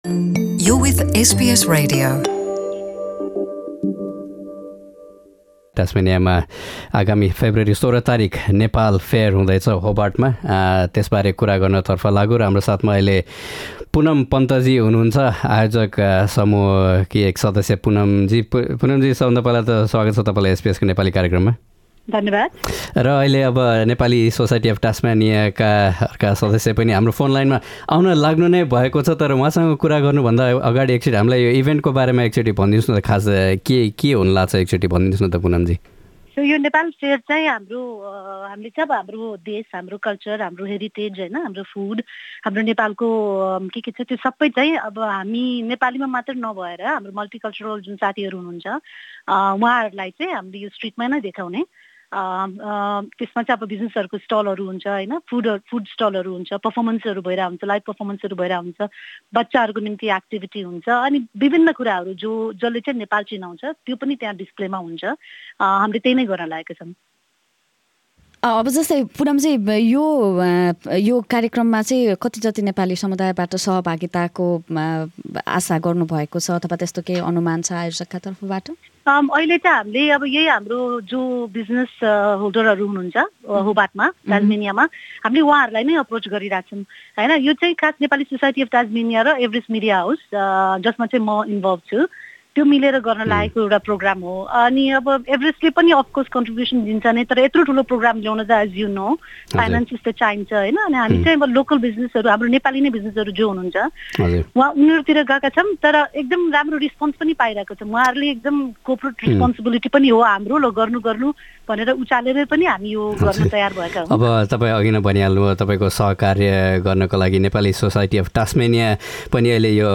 एसबीएस नेपाली पोडकास्ट